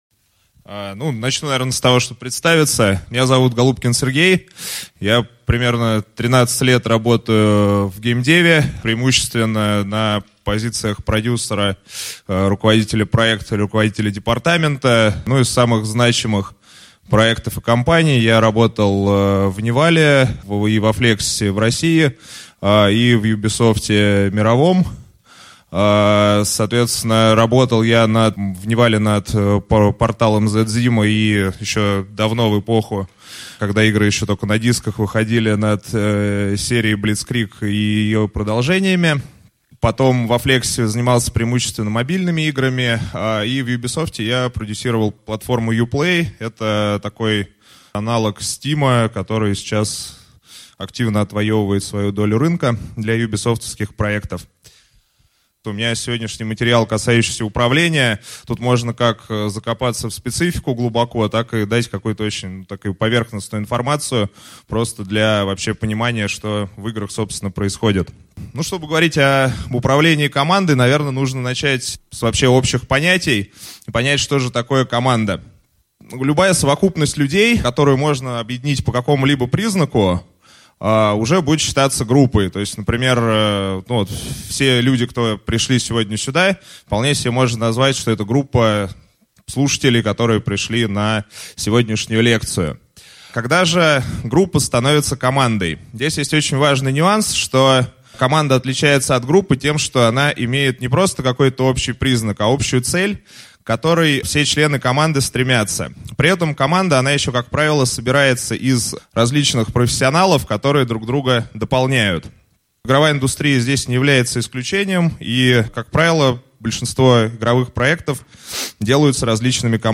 Аудиокнига Управление командой | Библиотека аудиокниг